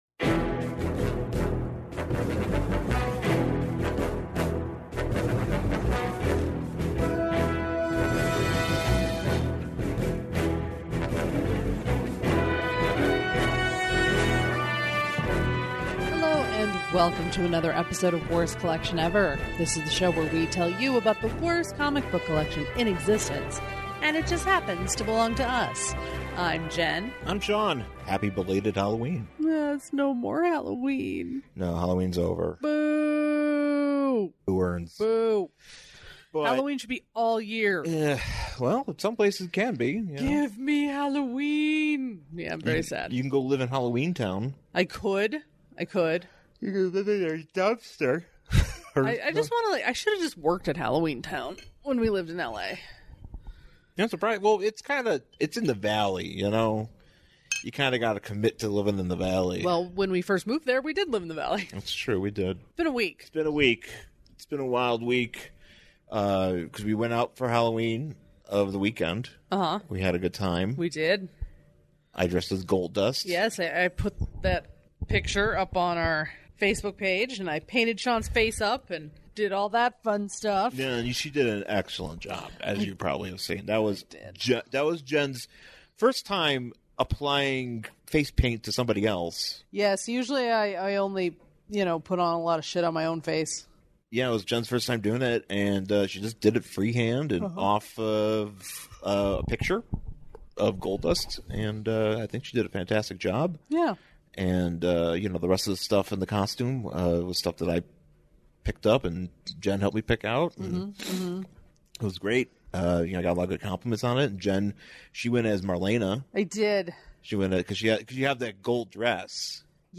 Also, we have some audio issues in part of this ep due to cat chicanery so bear with us.